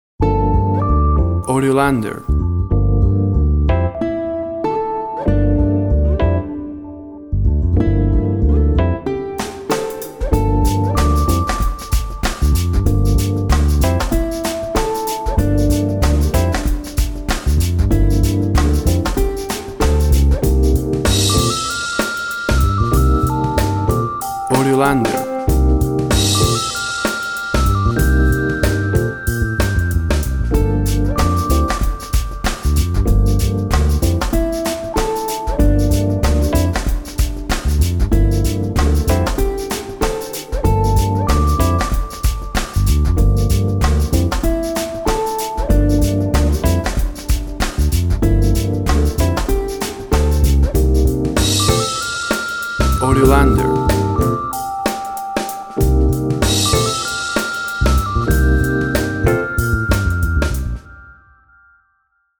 Tempo (BPM) 105